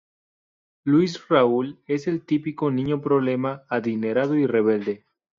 Pronounced as (IPA) /reˈbelde/